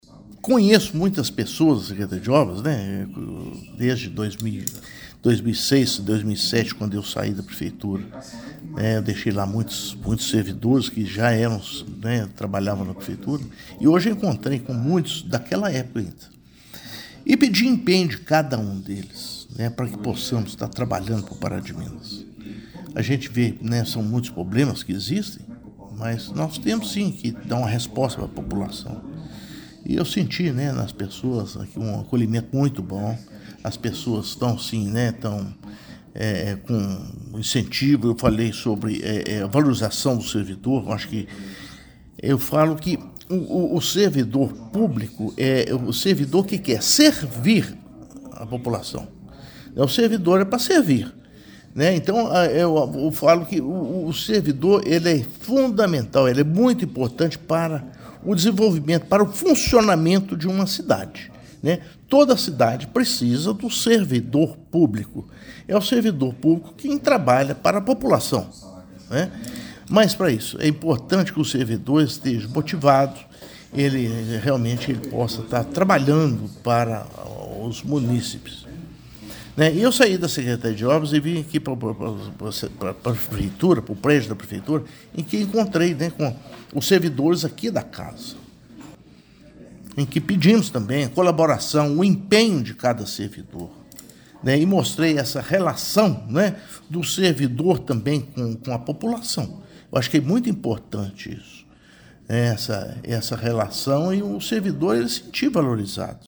Em seguida se reuniu com a equipe que trabalha no prédio da prefeitura, onde repetiu a necessidade de servir bem ao povo paraminense: